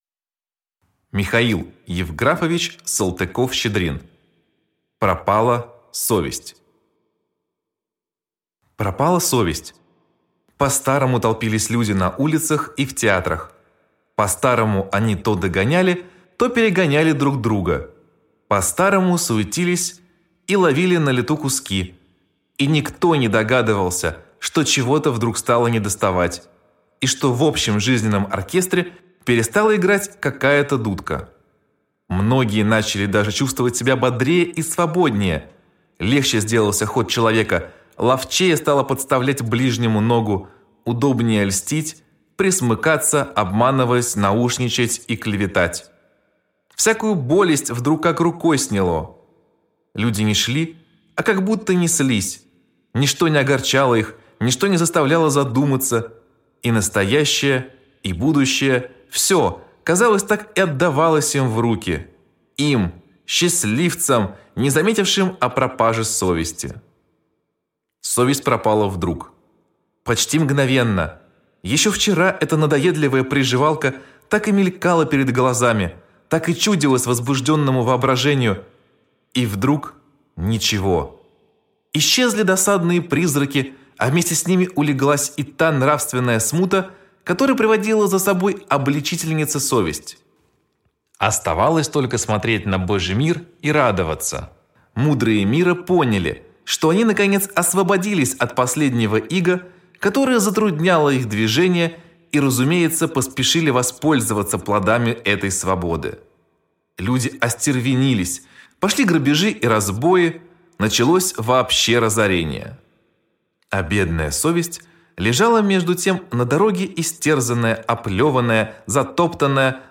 Аудиокнига Пропала совесть | Библиотека аудиокниг